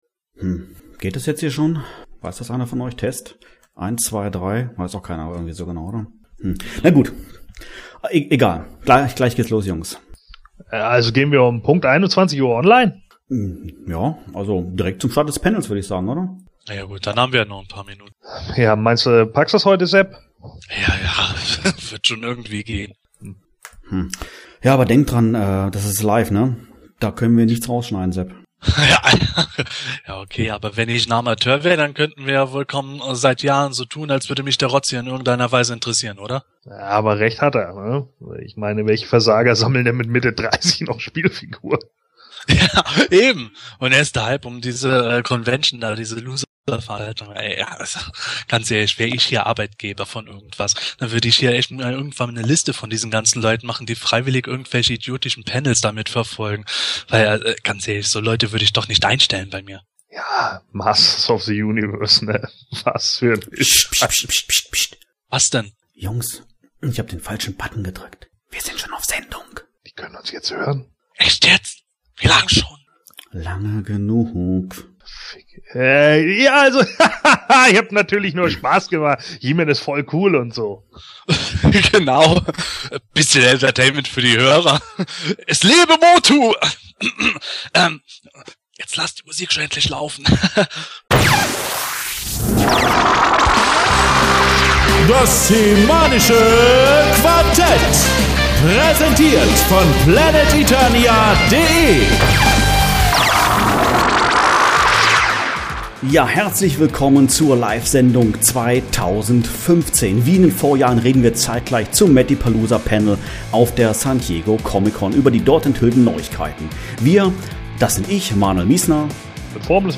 Wenn du die Live-Sendung verpasst haben solltest oder einfach noch einmal mit uns mitfiebern möchtest, kannst du dir jetzt hier die Episode in voller Länge noch einmal in Ruhe reinziehen.